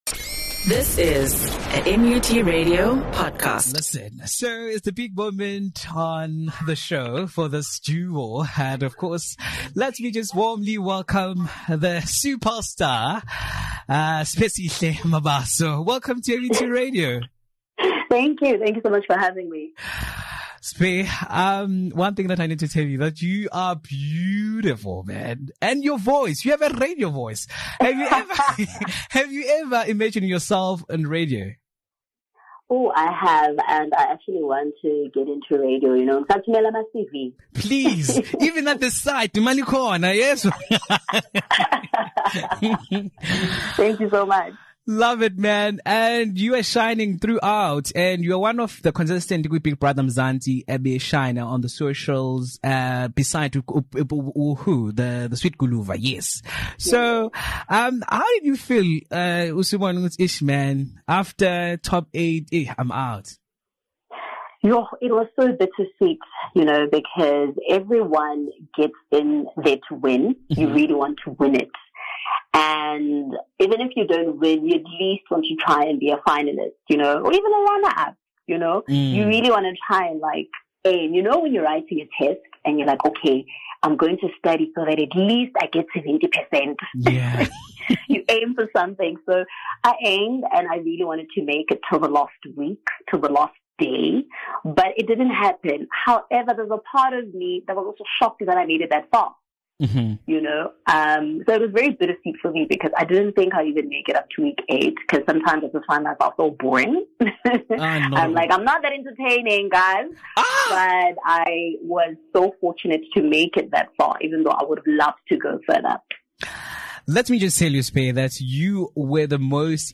had an interview